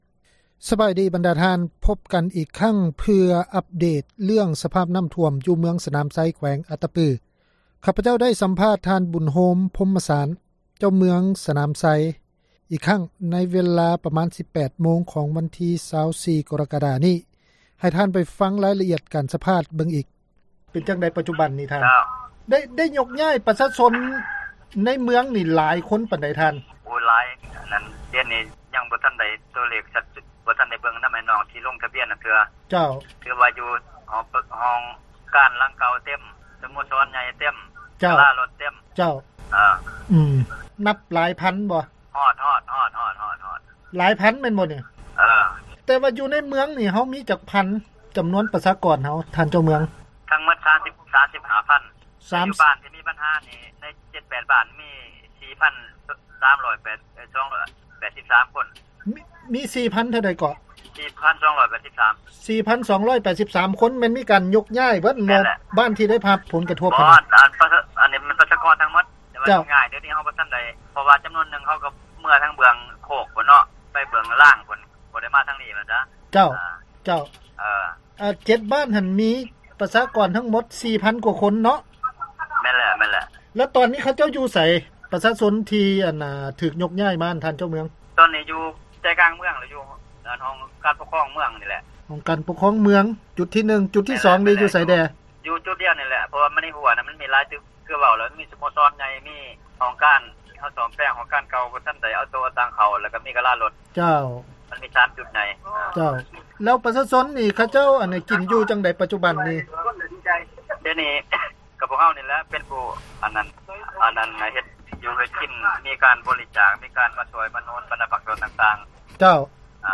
ວິທຍຸເອເຊັຽເສຣີ ໄດ້ຂໍສຳພາດ ເຈົ້າເມືອງສະໜາມໄຊ ເປັນຄັ້ງທີສອງ ໃນມື້ນີ້ ເພື່ອອັບເດດ ຄວາມຄືບໜ້າ ໃນ ສະຖານະການ ຊ່ອຍເຫຼືອ ປະຊາຊົນ ຈາກນ້ຳຖ້ວມ ໃນເວລາ ປະມານ 18 ໂມງແລງ ຂອງວັນທີ 24 ກໍຣະກະດາ 2018.